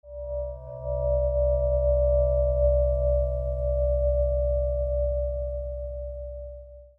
The Otto tuning forks are made from the highest quality aluminium, producing long and enduring tones. The Otto tuning forks produce low frequencies due to their long, weighted prongs.
C 64Hz Otto Tuning Fork
The C 64Hz produces low-frequency vibrations that are suggested to help loosen sacral ligaments.
64Hz-Tuning-Fork.mp3